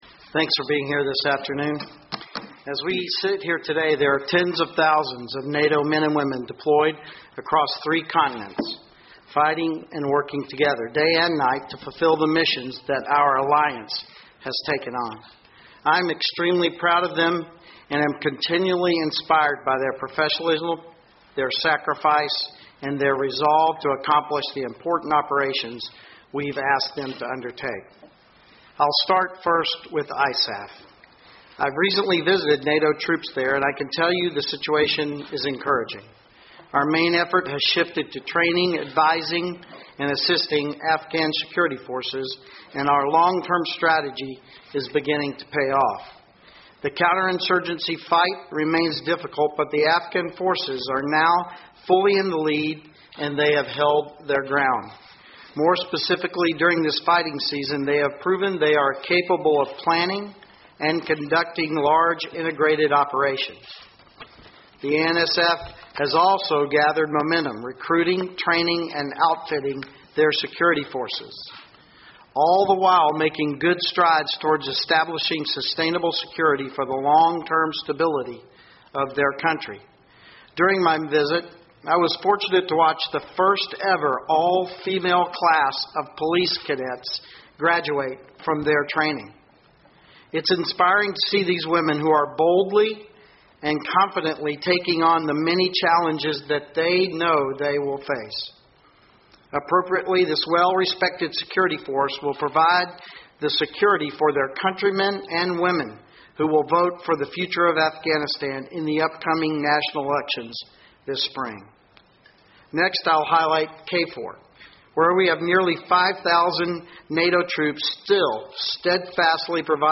Opening statement by the Supreme Allied Commander Europe, General Philip M. Breedlove, at the joint press point following the 170th NATO Chiefs of Defence meeting